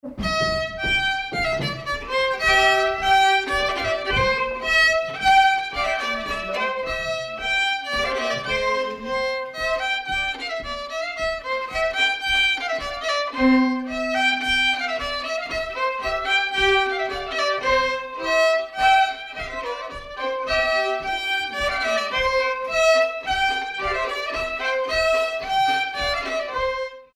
danse : polka piquée
circonstance : bal, dancerie
Pièce musicale inédite